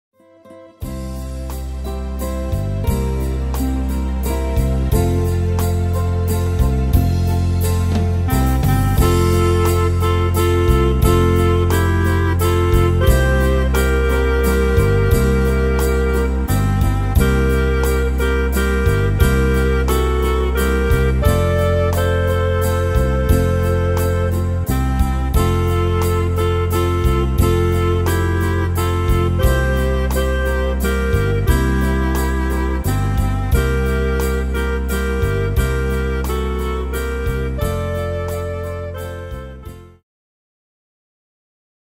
Tempo: 88 / Tonart: F-Dur